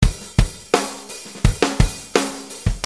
SAMPLES DE BATERIA
¿Te gustan los samplers de ritmos y redobles de batería?, aquí tengo unos cuantos bajados de Internet a tu disposición en formato mp3.